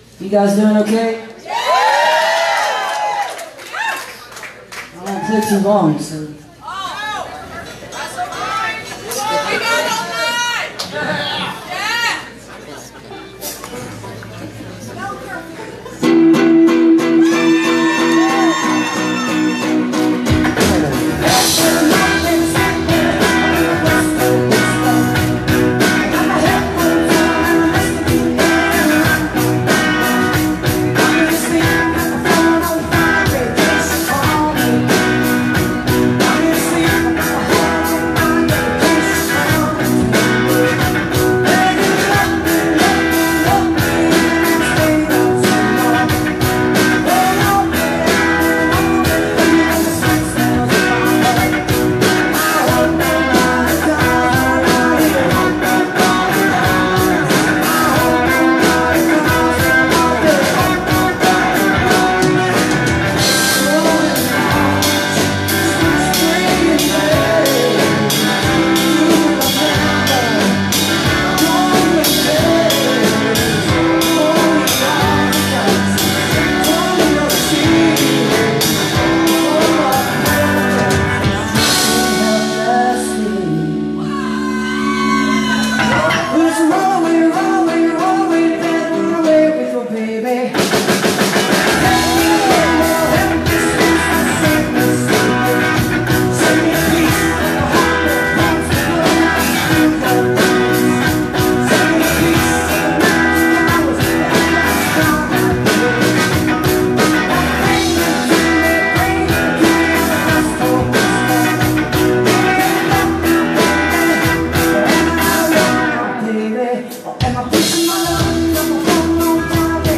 (captured from facebook live stream)